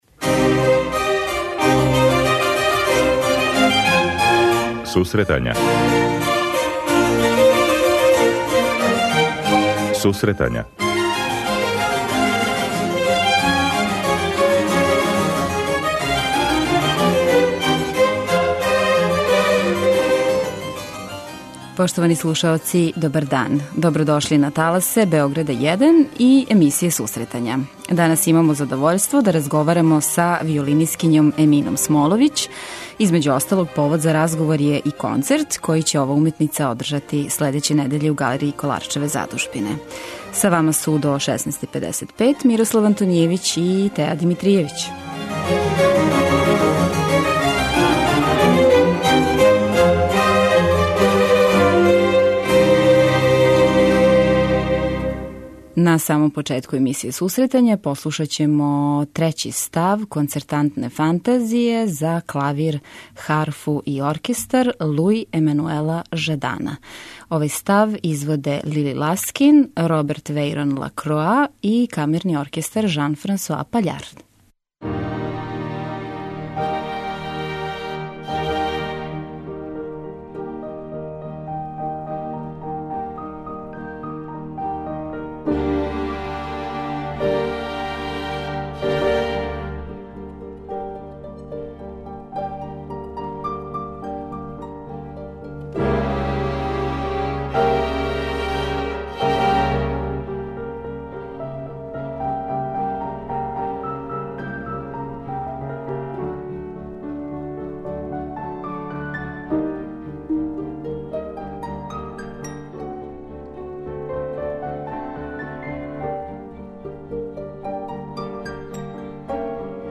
преузми : 26.24 MB Сусретања Autor: Музичка редакција Емисија за оне који воле уметничку музику.